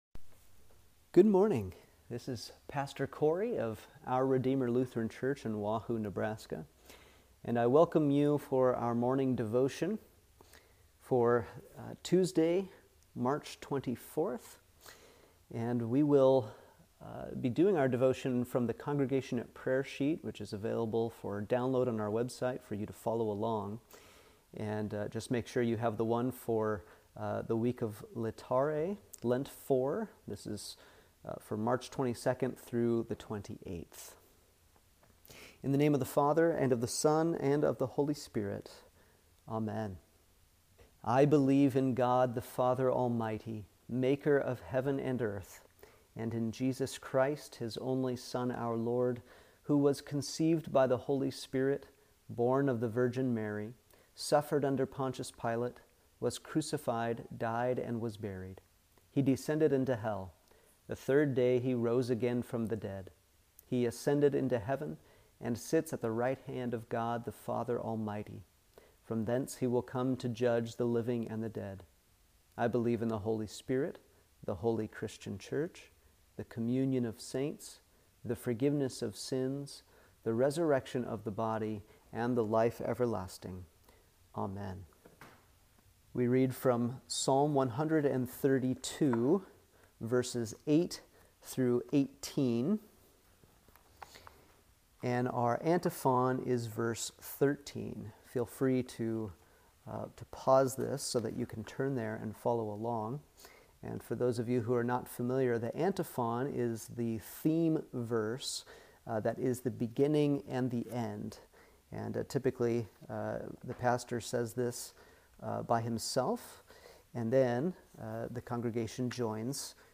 Morning Devotion for Tuesday, March 24th